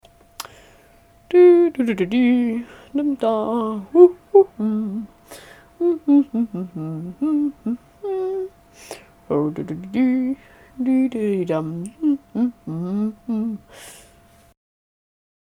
Banana_humming.mp3